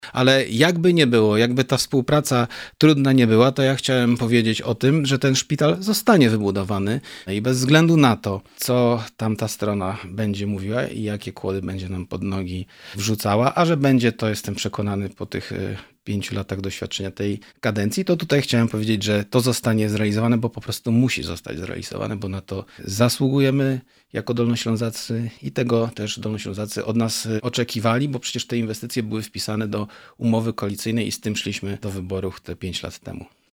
Szpital onkologiczny zostanie wybudowany zadeklarował na naszej antenie wicemarszałek.